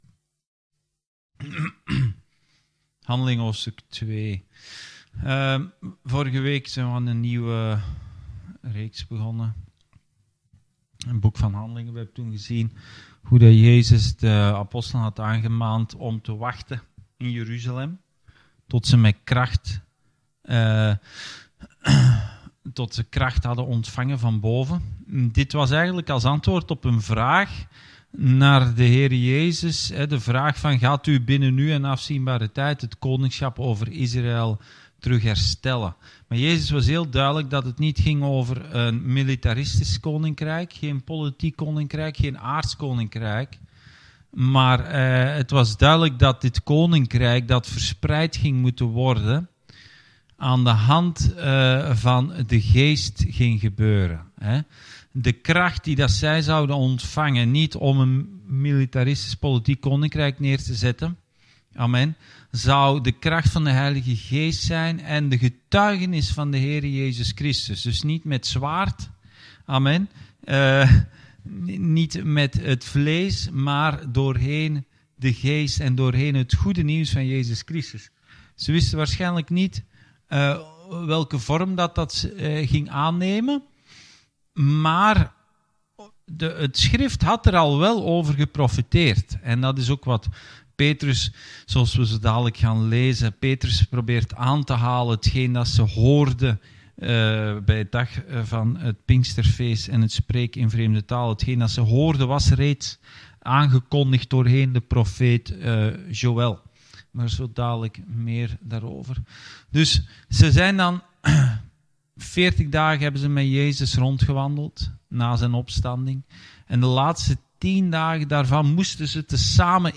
Bijbelstudie: Handelingen 2